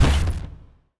sci-fi_weapon_auto_turret_release_02.wav